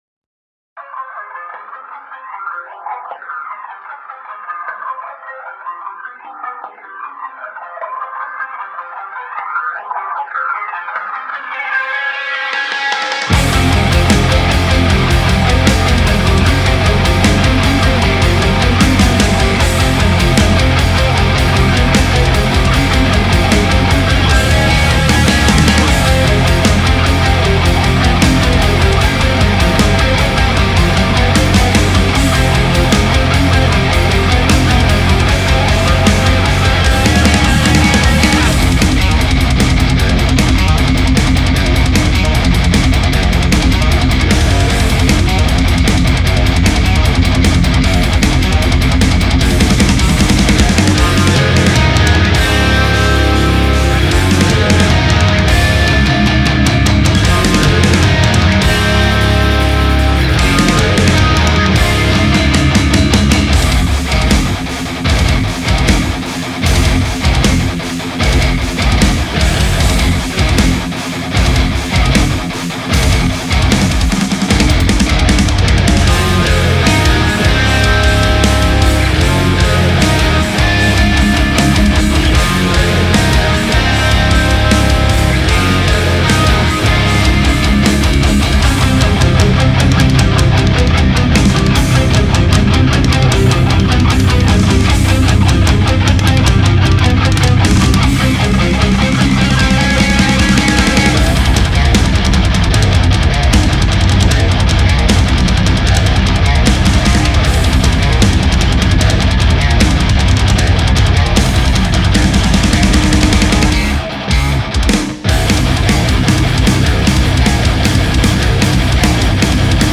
Out Of The Crypt (metal instrumental